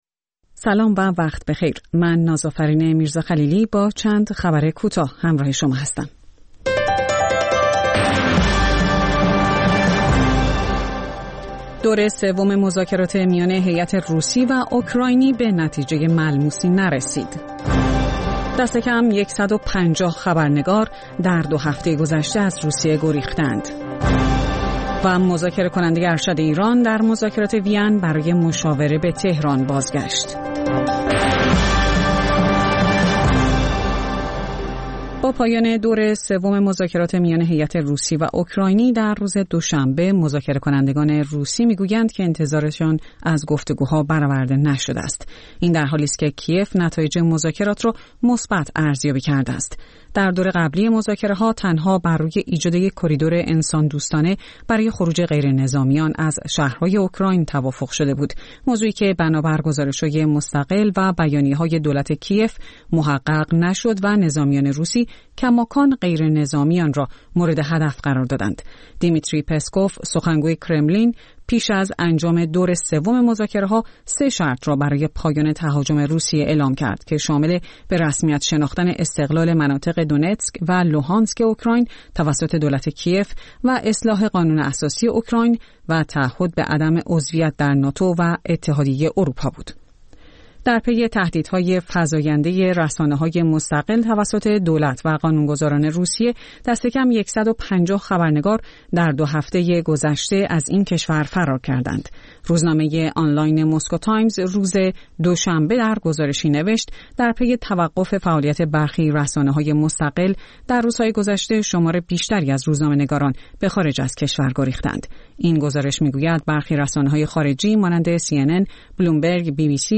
سرخط خبرها ۳:۰۰